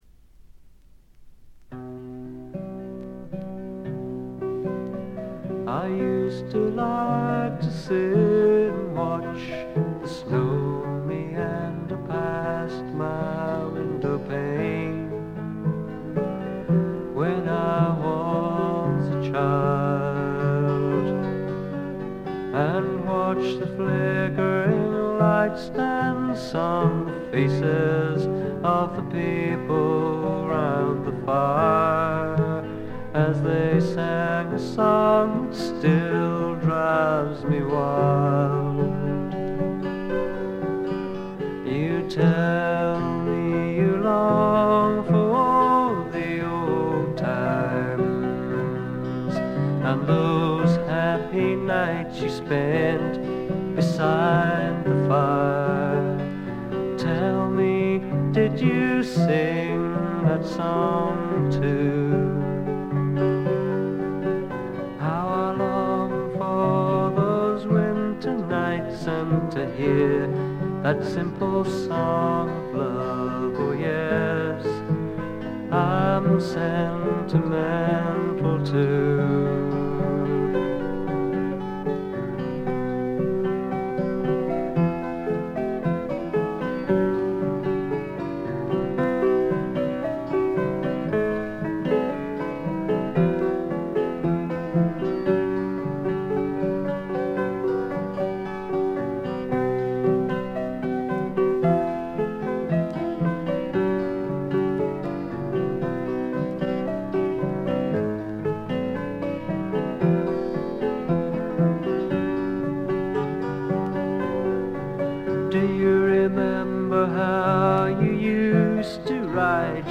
部分視聴ですが、ほとんどノイズ感無し。
全編を通じて飾り気のないシンプルな演奏で「木漏れ日フォーク」ならぬ「黄昏フォーク」といったおもむきですかね。
試聴曲は現品からの取り込み音源です。